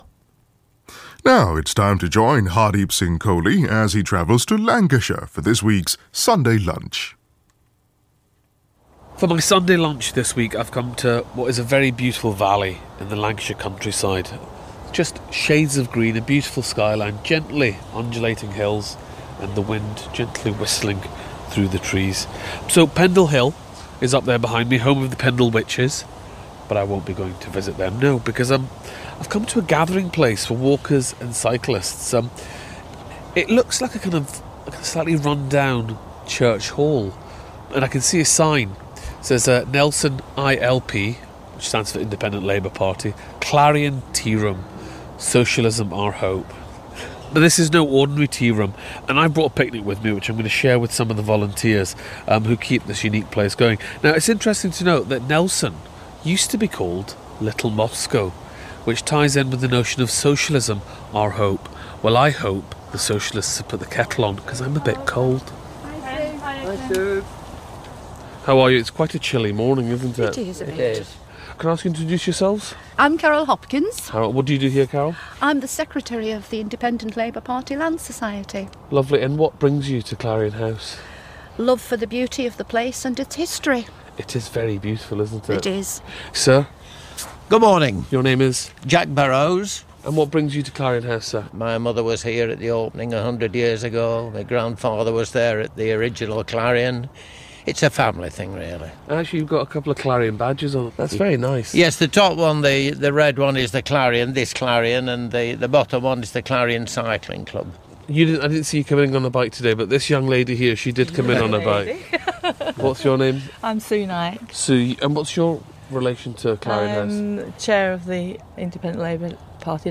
Hardeep Singh Kohli visits a beautiful valley in Lancashire to meet the volunteers who run Clarion House.